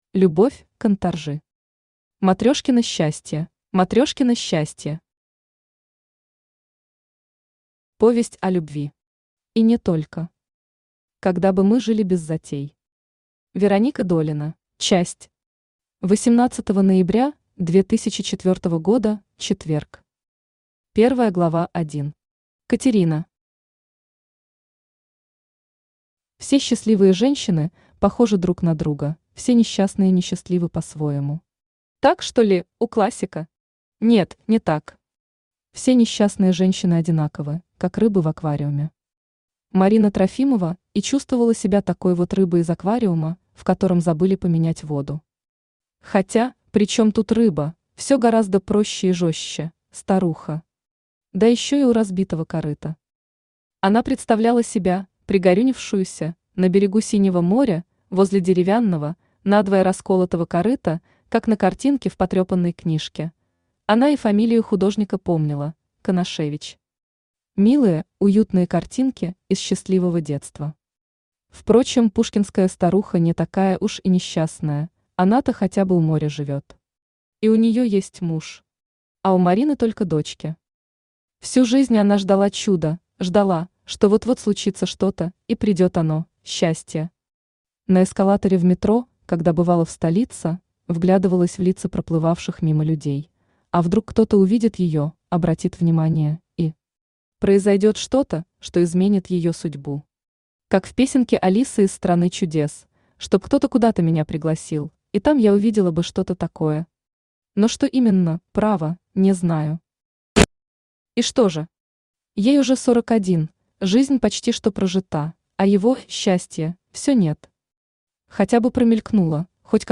Аудиокнига Матрёшкино счастье | Библиотека аудиокниг
Aудиокнига Матрёшкино счастье Автор Любовь Кантаржи Читает аудиокнигу Авточтец ЛитРес.